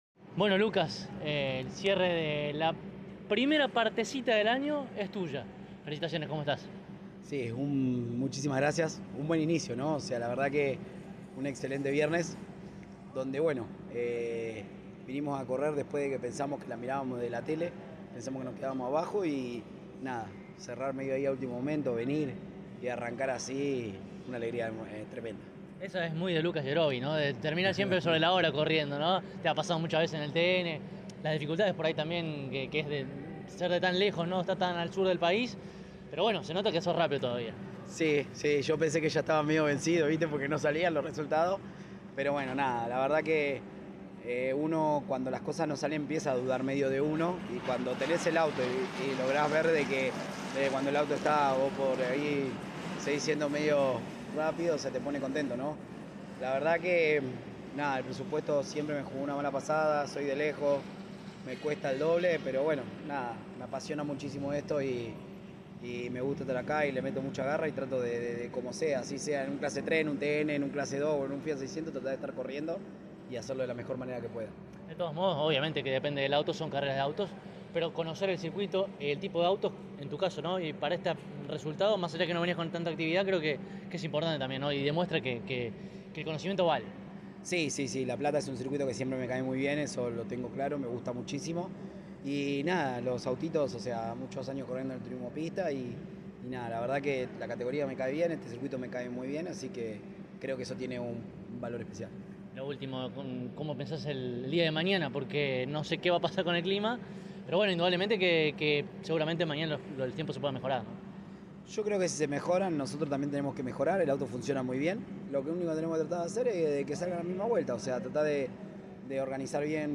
en diálogo con CÓRDOBA COMPETICIÓN